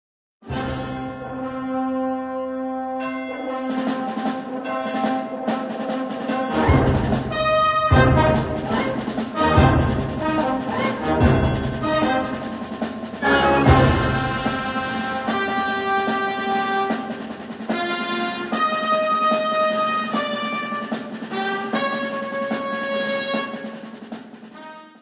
Unterkategorie Suite
1 Allegro maestoso 2:48